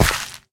sounds / step / gravel4.ogg
gravel4.ogg